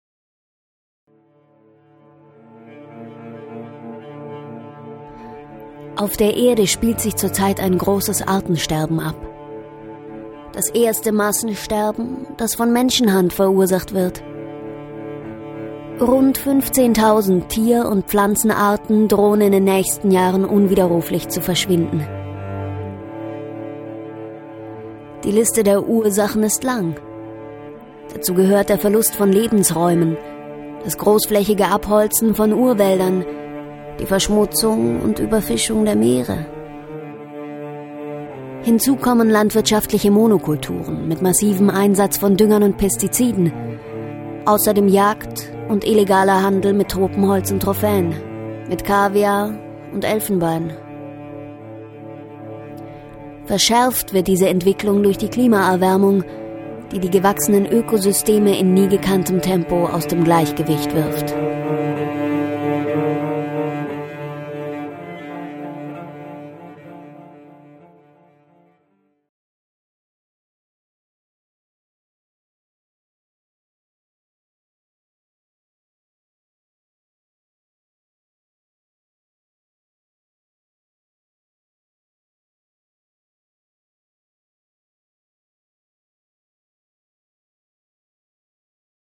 Sprecherin Werbung Stimme Welt Kompakt sachlich seriös sinnlich frech lieblich kindlich derb erotisch Hörbuch Dokumentation Reportage Synchron ausgebildet
Sprechprobe: Industrie (Muttersprache):
professional narrator and voice-over artist with a unique voice and expression